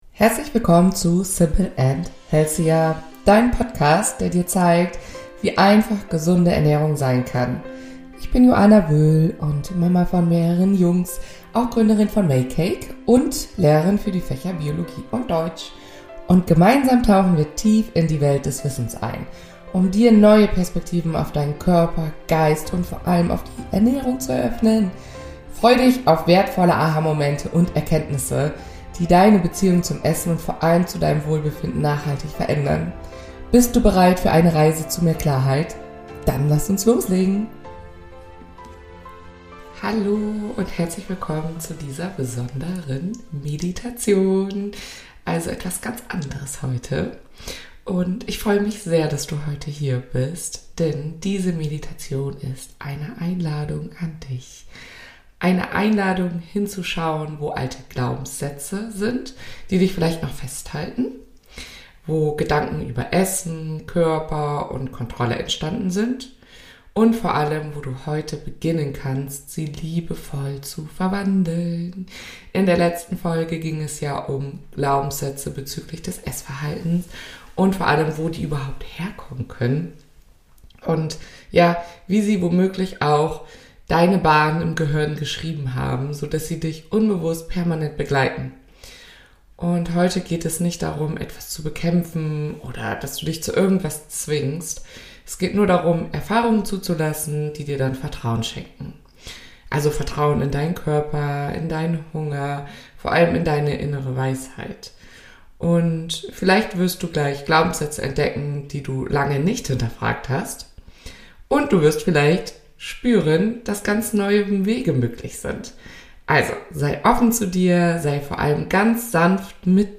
In dieser Meditation lade ich dich ein, deine alten Glaubenssätze rund um Essen, Kontrolle und Körperbewusstsein zu erkennen – und sie sanft zu transformieren.